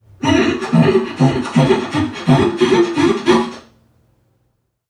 NPC_Creatures_Vocalisations_Robothead [88].wav